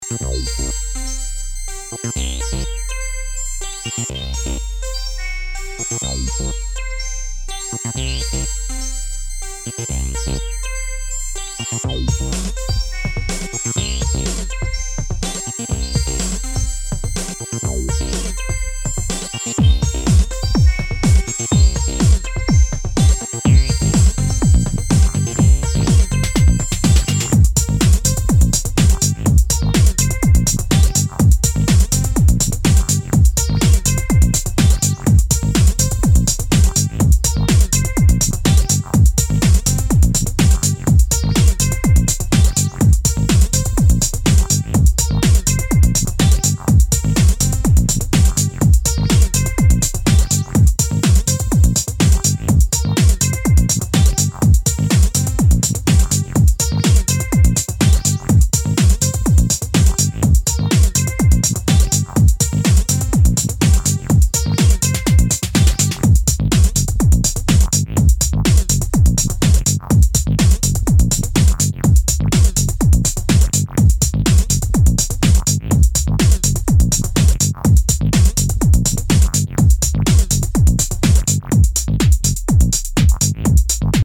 4 dancefloor burners